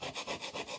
磨铁棒.mp3